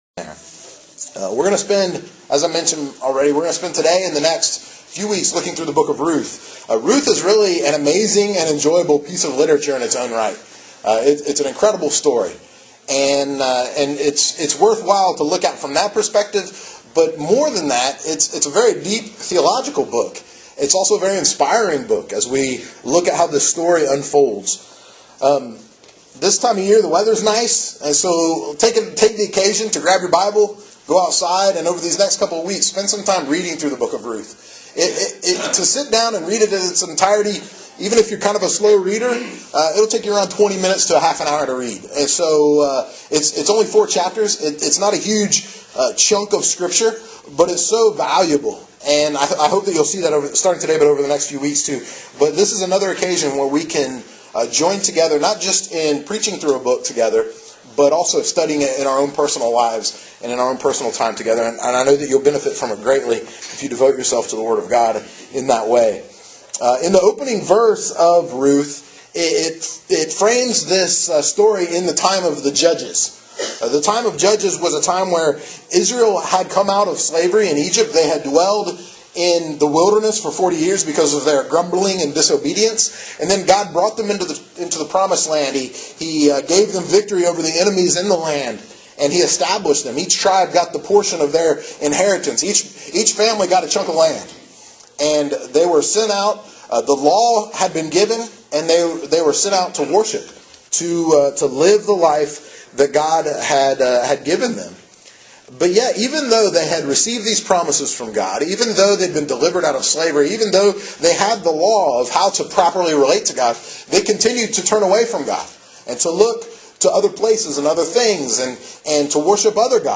Part one of a five part teaching series through the Old Testament Book of Ruth